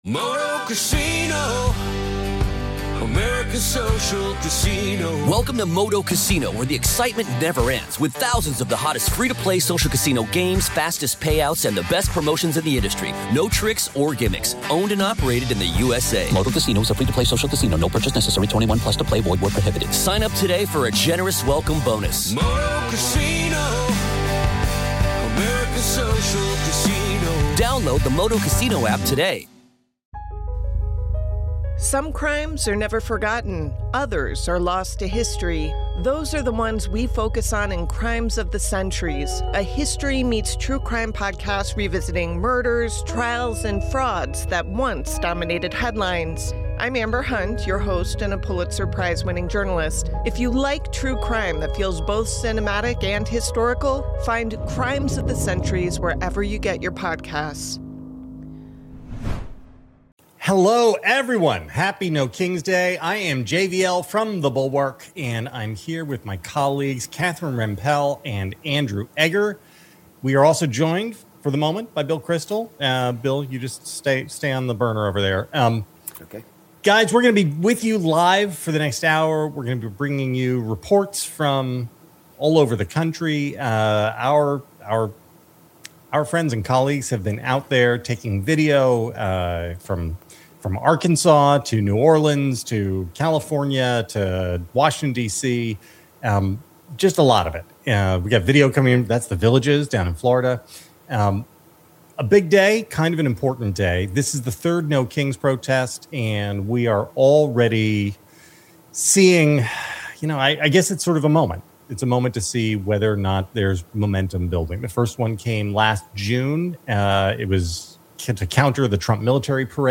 many others from The Bulwark for live "No Kings" rally coverage.